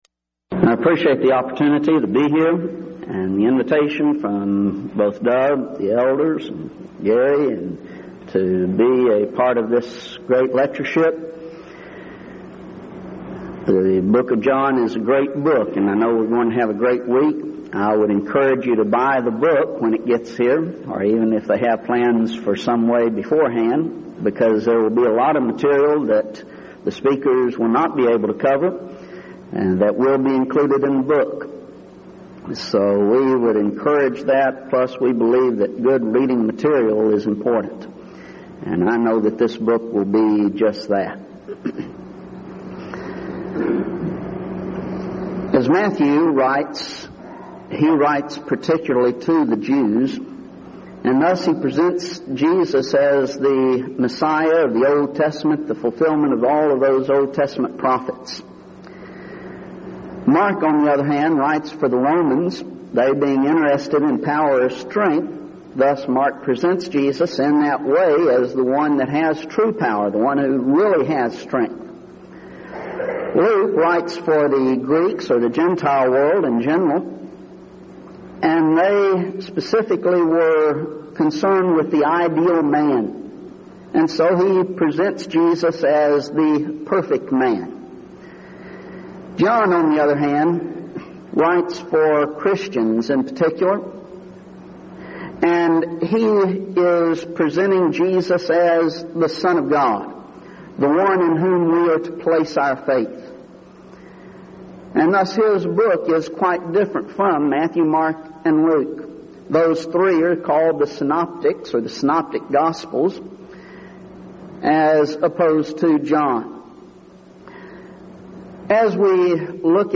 Event: 1999 Denton Lectures Theme/Title: Studies in the Book of John